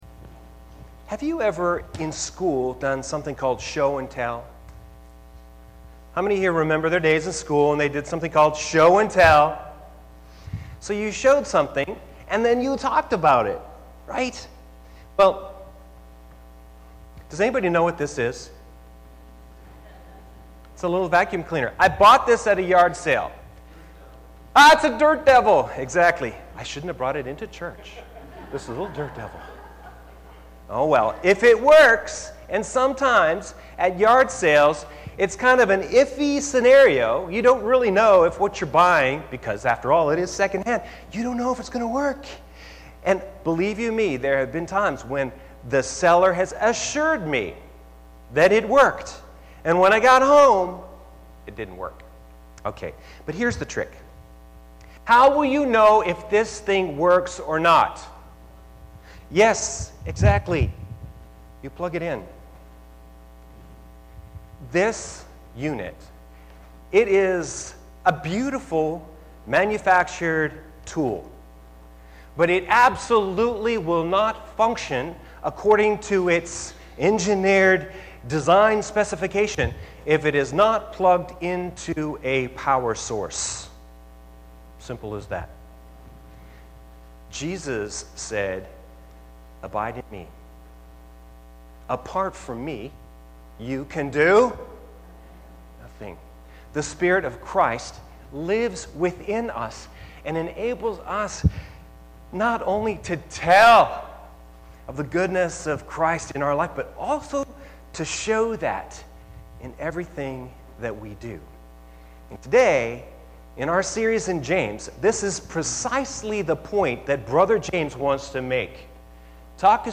Sermon 10-21-18